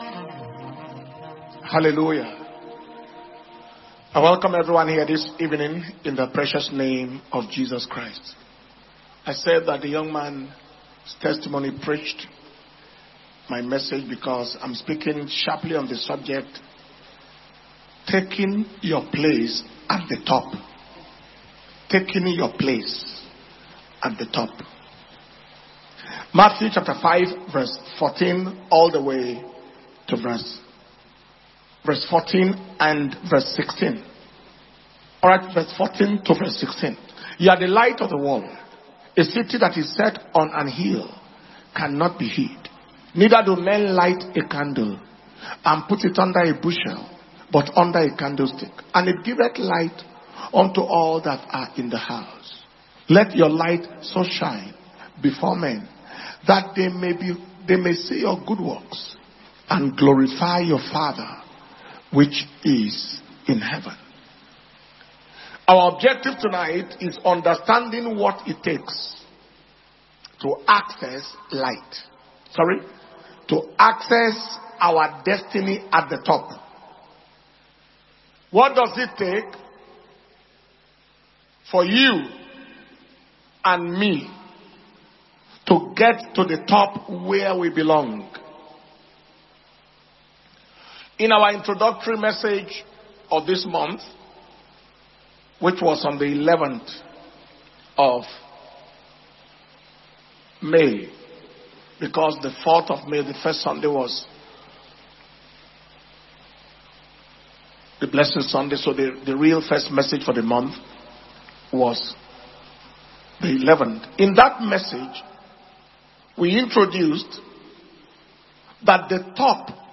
Power Communion Service